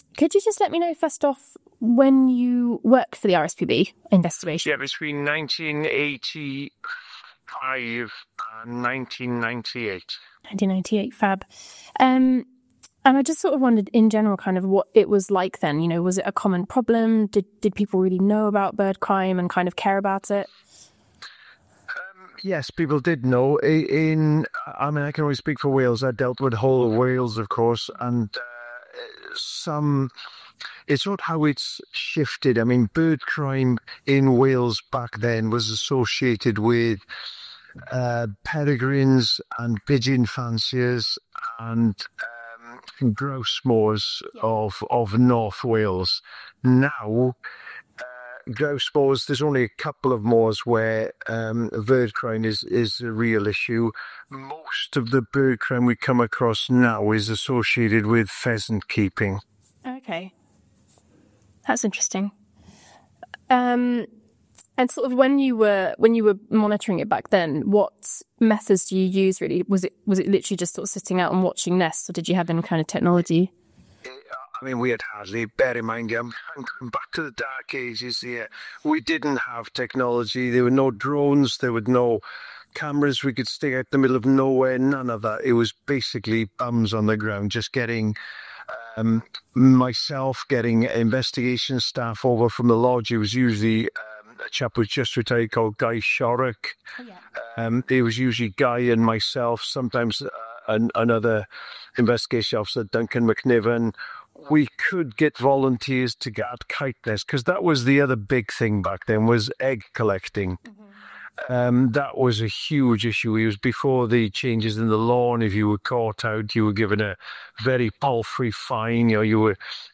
Listen to the full interview of presenter and former Birdcrime Investigator Iolo Williams
FINAL-Iolo-interviewF3.mp3